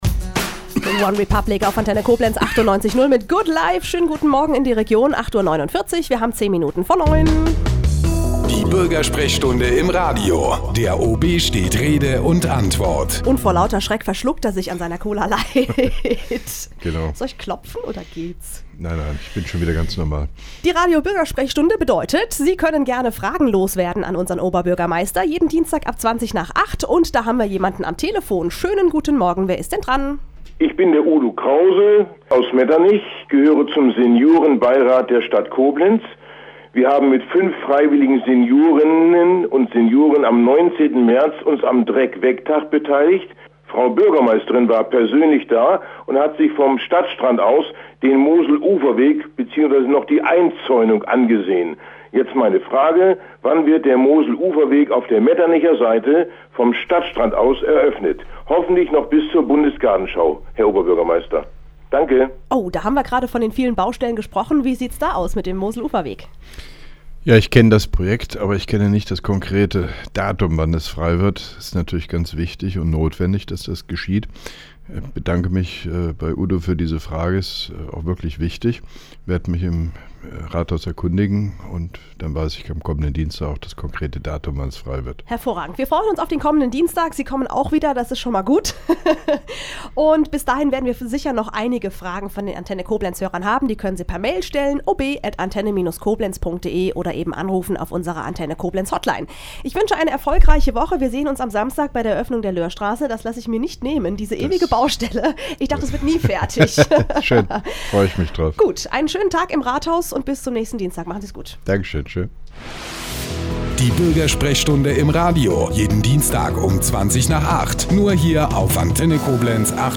(4) Koblenzer Radio-Bürgersprechstunde mit OB Hofmann-Göttig 05.04.2011